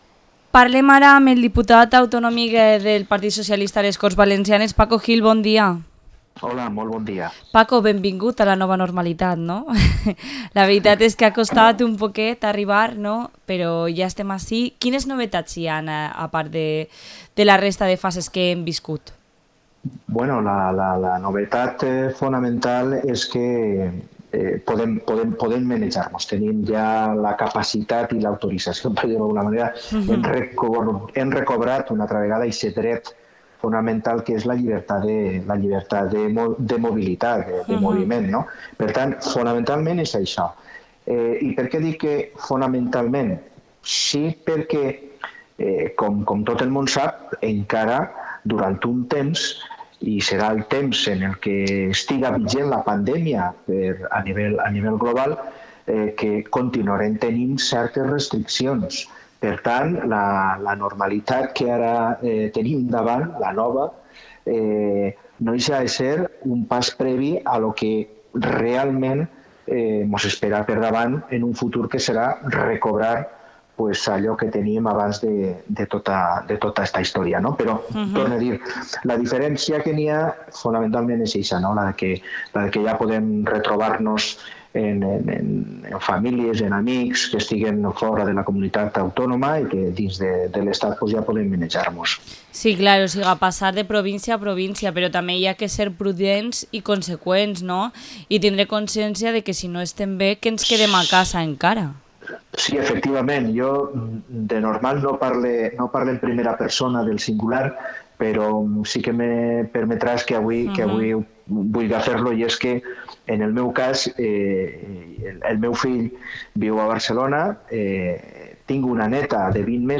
Entrevista al diputado autonómico del PSPV-PSOE, Paco Gil